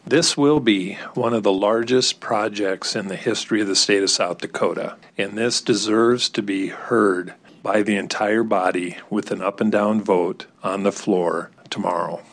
Rapid City Representative Mike Derby sent it to the House floor the same way.